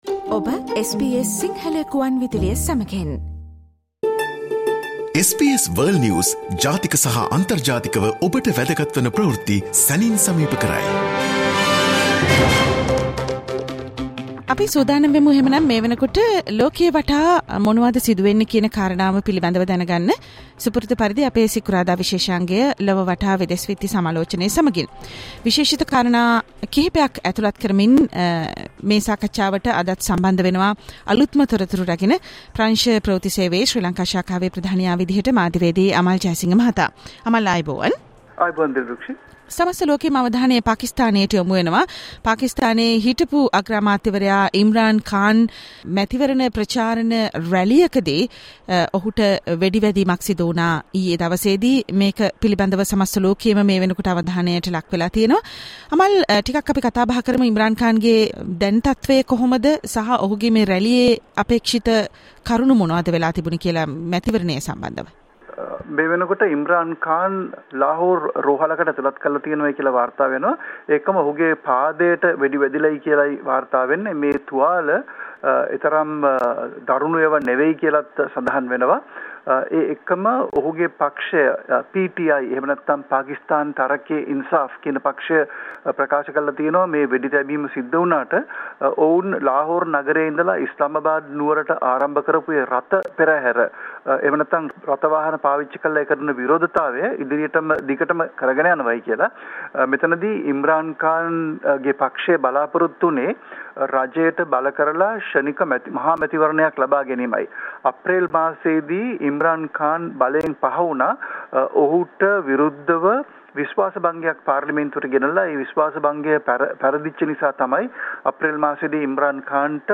listen to the SBS Sinhala Radio weekly world News wrap every Friday Want to know more about the latest COVID conditions in Australia?